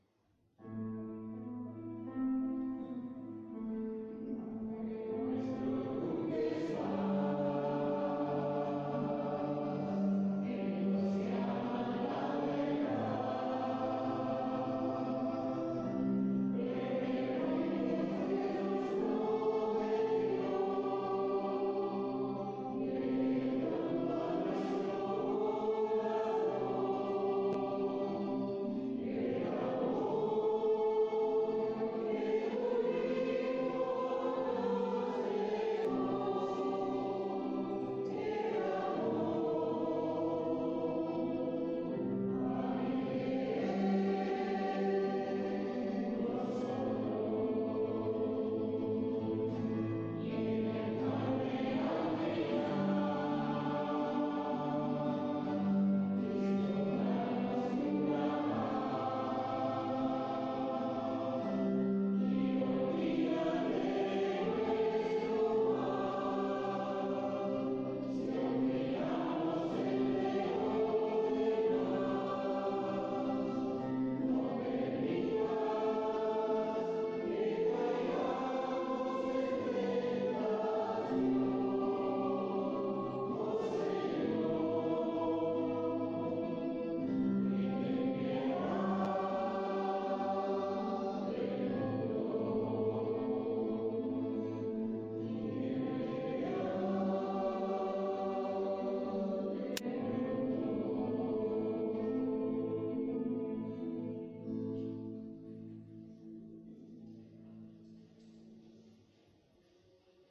Pregària de Taizé a Mataró... des de febrer de 2001
Església del Sagrat Cor - Diumenge 29 de maig de 2017